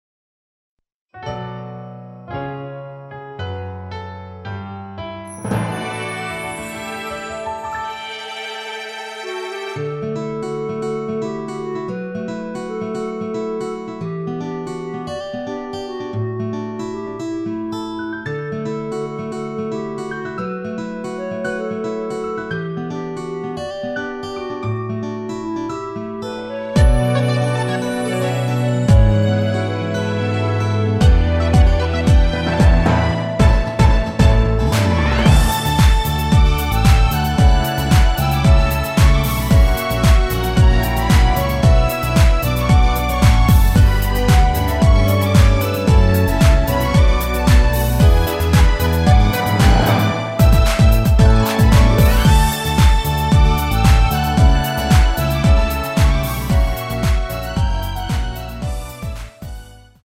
(+2)멜로디포함된 MR 입니다.(미리듣기 참조)
앞부분30초, 뒷부분30초씩 편집해서 올려 드리고 있습니다.
곡명 옆 (-1)은 반음 내림, (+1)은 반음 올림 입니다.
(멜로디 MR)은 가이드 멜로디가 포함된 MR 입니다.